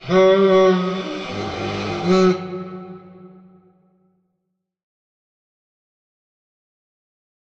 Minecraft Version Minecraft Version latest Latest Release | Latest Snapshot latest / assets / minecraft / sounds / item / goat_horn / call4.ogg Compare With Compare With Latest Release | Latest Snapshot